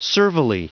Prononciation du mot servilely en anglais (fichier audio)